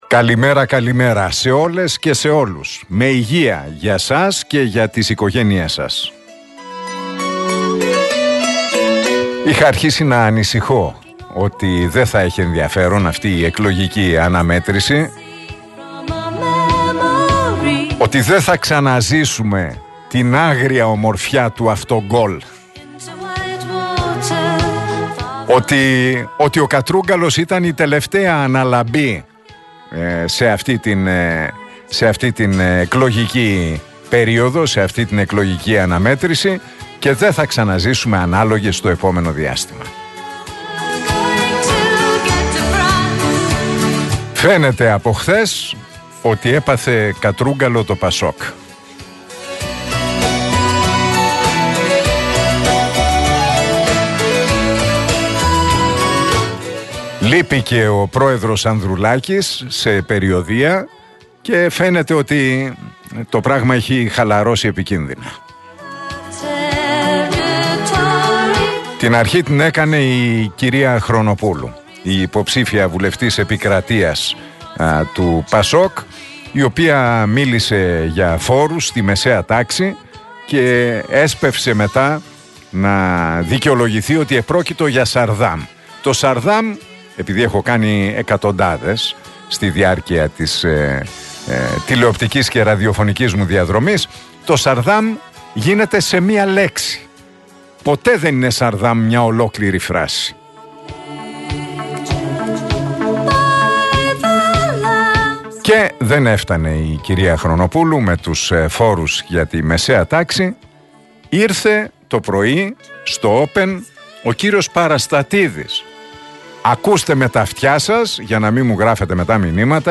Ακούστε το σχόλιο του Νίκου Χατζηνικολάου στον RealFm 97,8, την Τετάρτη 31 Μαΐου 2023.